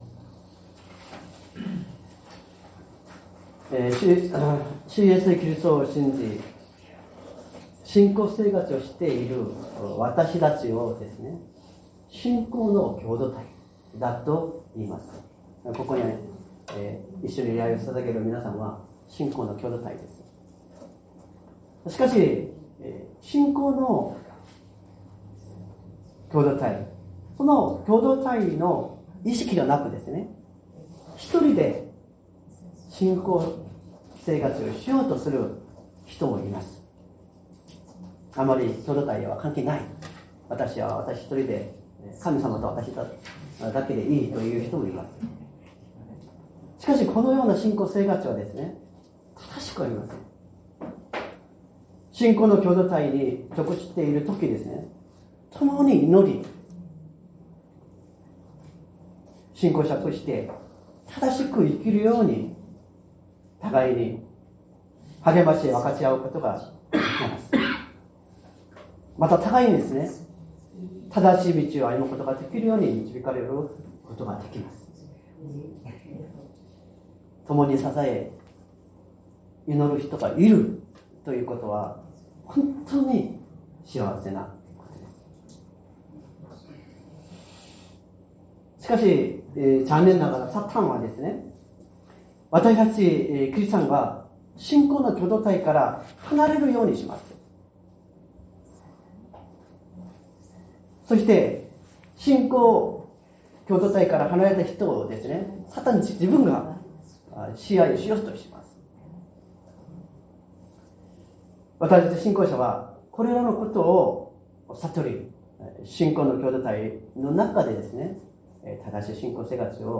善通寺教会。説教アーカイブ 2025年04月06日朝の礼拝「帰還」
音声ファイル 礼拝説教を録音した音声ファイルを公開しています。